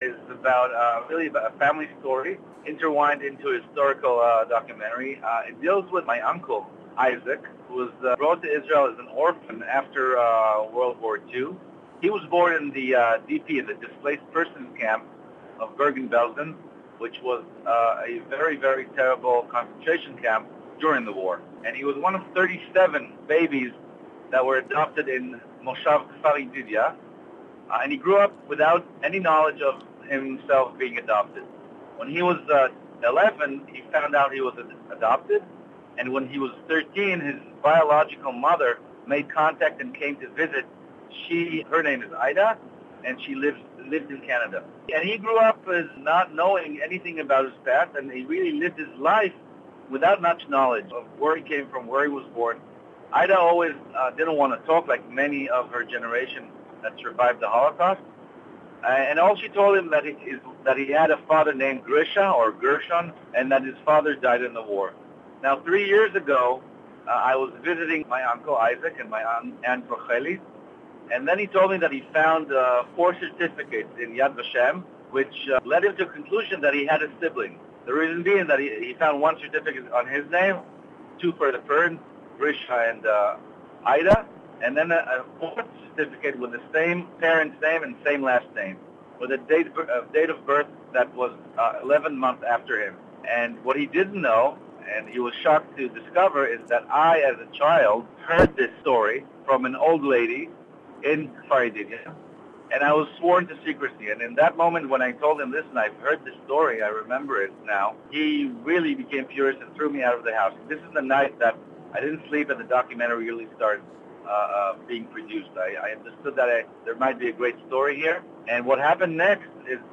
English interview